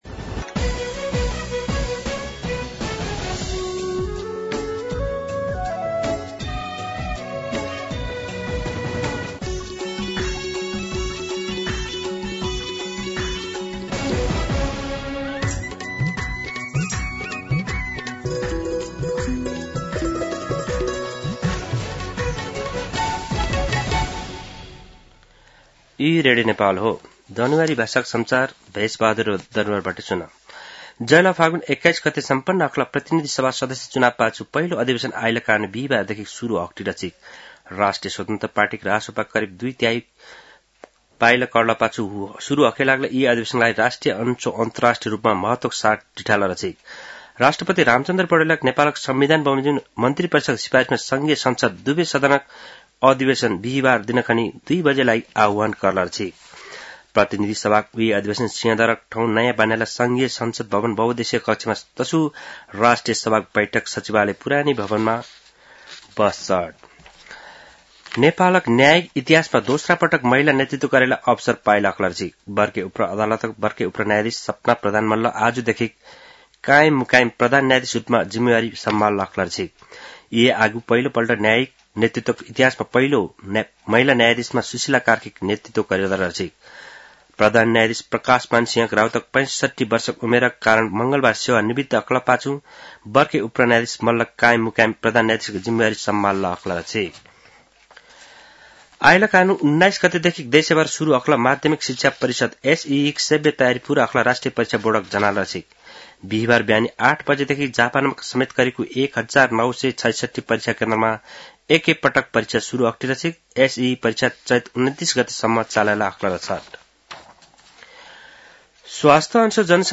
दनुवार भाषामा समाचार : १८ चैत , २०८२
Danuwar-News.mp3